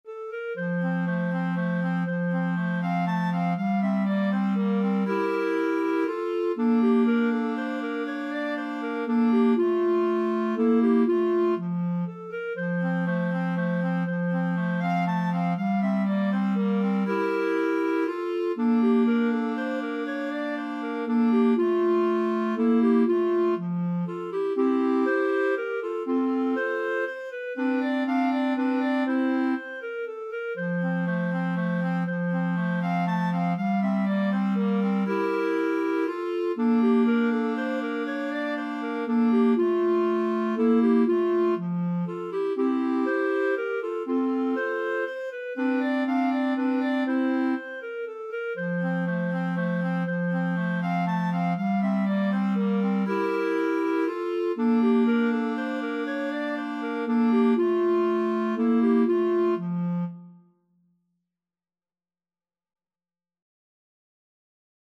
3/4 (View more 3/4 Music)
Clarinet Trio  (View more Easy Clarinet Trio Music)
Classical (View more Classical Clarinet Trio Music)